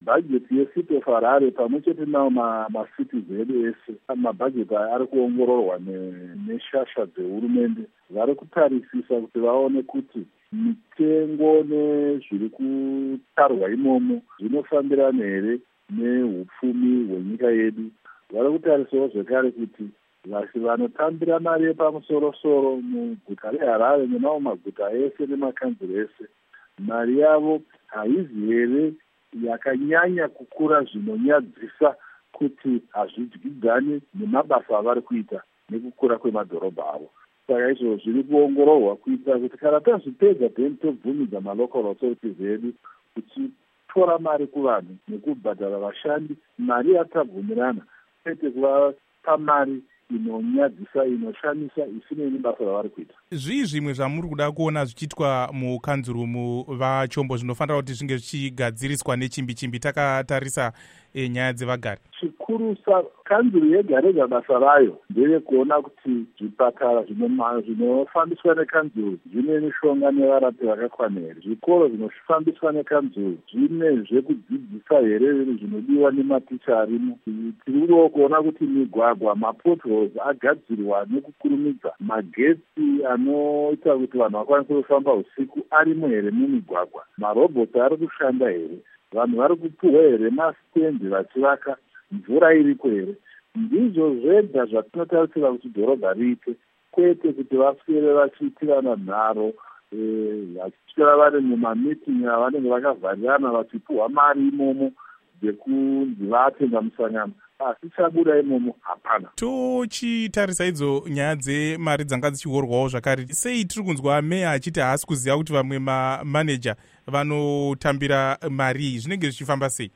Hurukuro naVaIgnatious Chombo